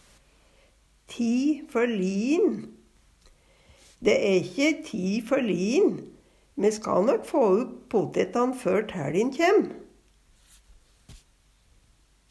DIALEKTORD PÅ NORMERT NORSK ti førli'in det er enno tidsnok, det er ikkje for seint Eksempel på bruk Dæ æ ikkje nokko ti førli'in.